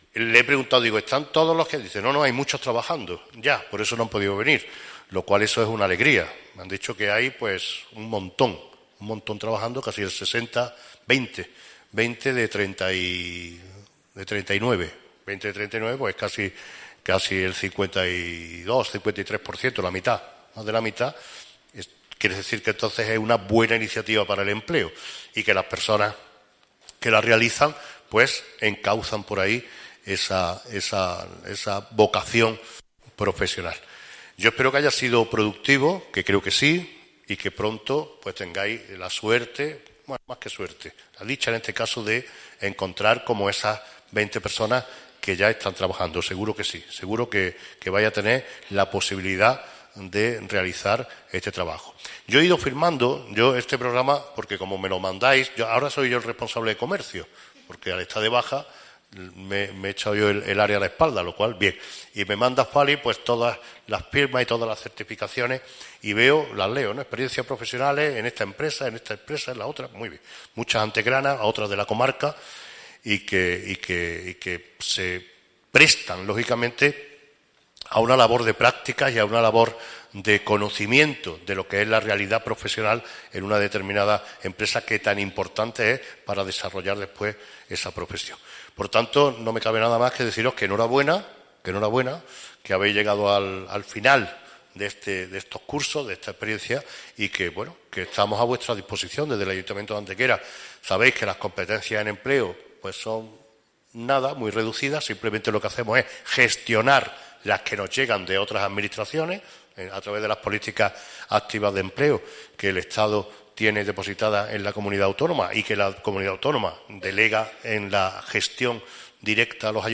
El alcalde de Antequera, Manolo Barón, la teniente de alcalde Elena Melero y la concejal Sara Ríos han presidido en el mediodía de hoy martes el acto de entrega de diplomas a las 38 personas que ya han concluido un ciclo de prácticas profesionales dentro del programa de Experiencias Profesionales para el Empleo (EPEs) que se desarrolla en nuestra ciudad desde el 5 de marzo de 2019 hasta el 27 de diciembre del presente año 2020.
Cortes de voz